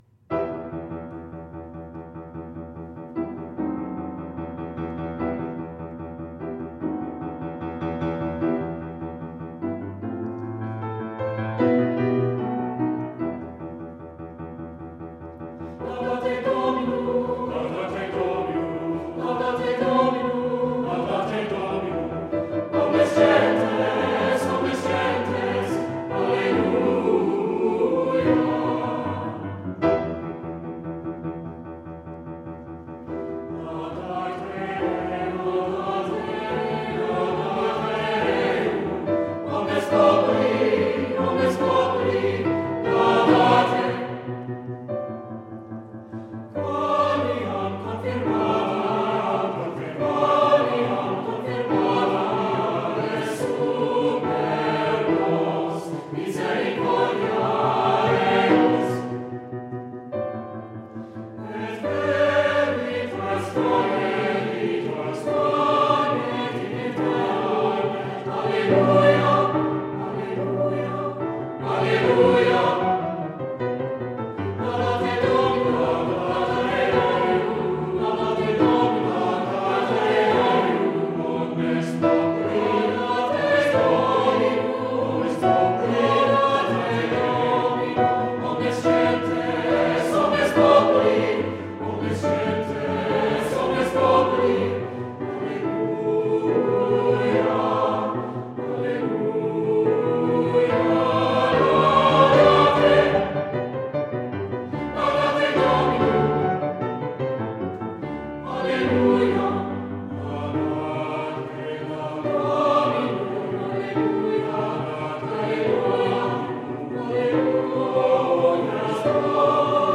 Voicing: "SA(T)B or Two-Part Any Combination"